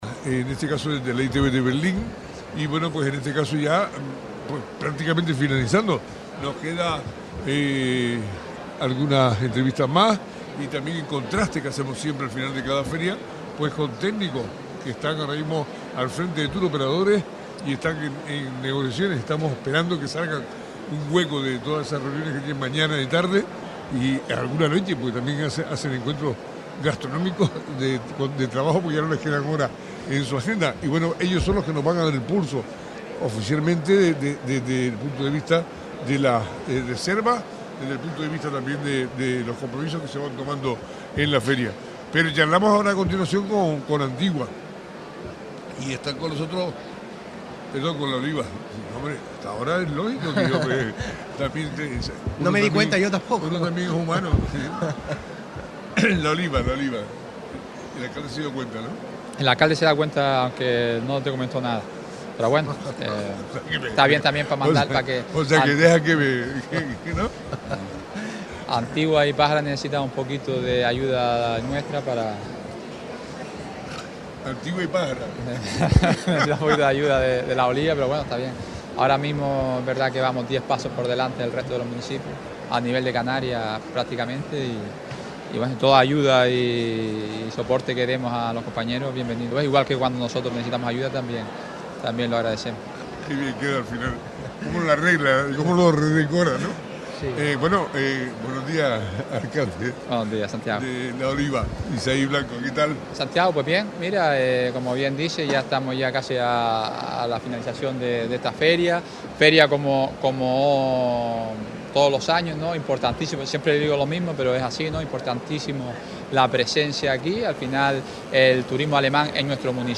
Así lo señaló en el especial de Radio Sintonía en la ITB el alcalde de La Oliva, Isaí Blanco quien acompañado del concejal de Turismo David Fajardo, destacó la importancia de la presencia del municipio del norte de Fuerteventura en este encuentro internacional.